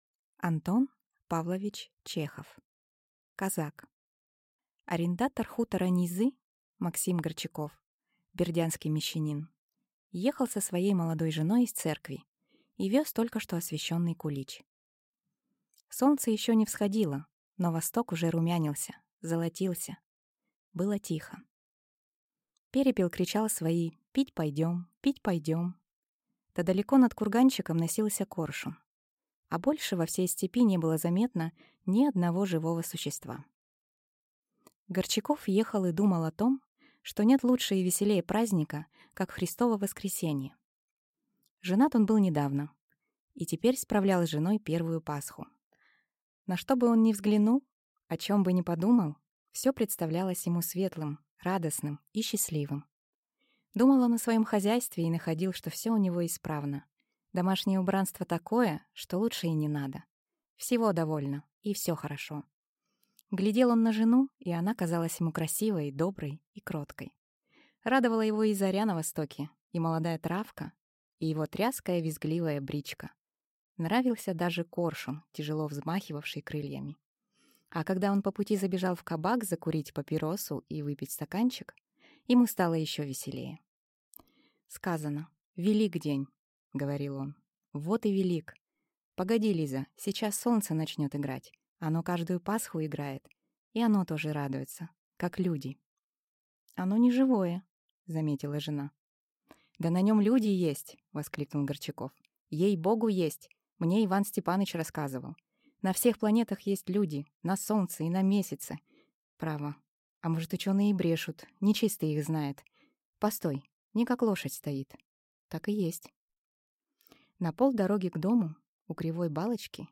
Аудиокнига Казак | Библиотека аудиокниг